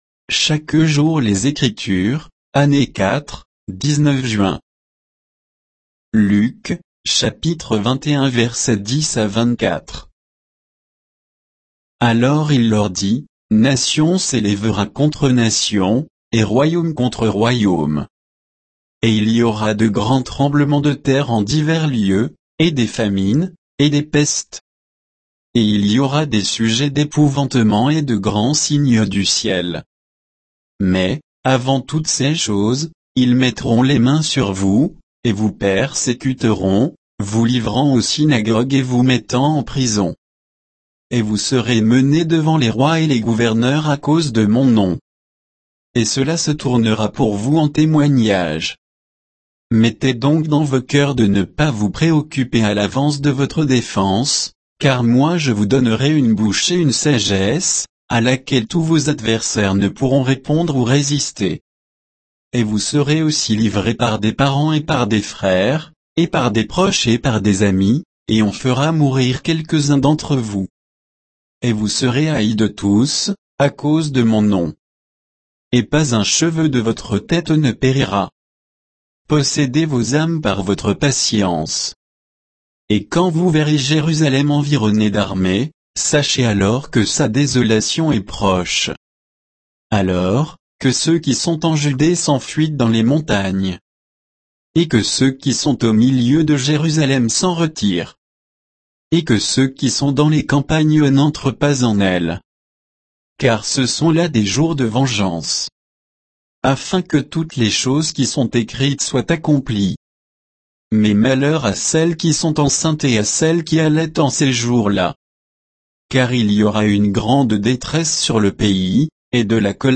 Méditation quoditienne de Chaque jour les Écritures sur Luc 21